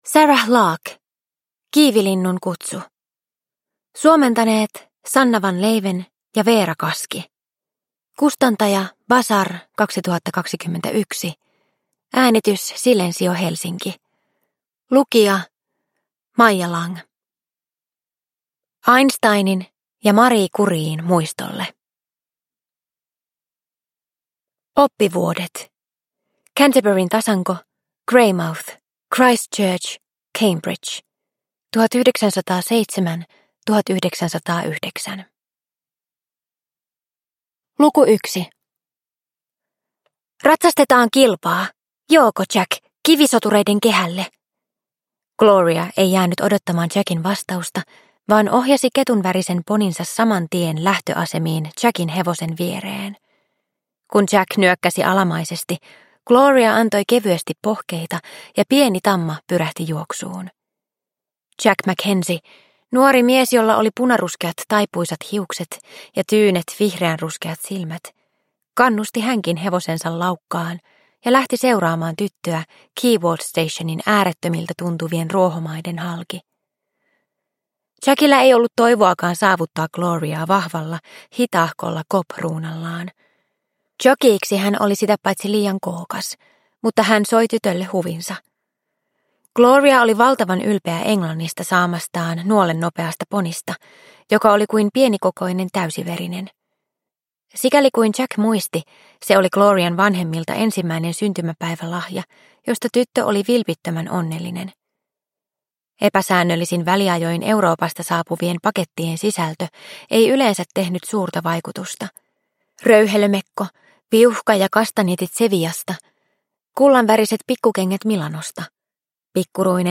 Kiivilinnun kutsu – Ljudbok – Laddas ner